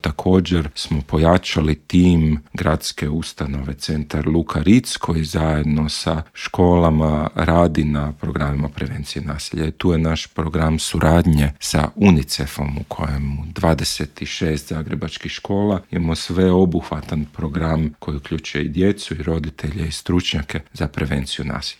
Početak ove školske godine u gradu Zagrebu obilježio je niz slučajeva međuvršnjačkog nasilja o kojem smo, u Intervjuu Media servisa, razgovarali sa pročelnikom zagrebačkog Gradskog ureda za obrazovanje, sport i mlade Lukom Jurošem. Rekao nam je da je Grad ove godine u sigurnost škola uložio pet milijuna eura.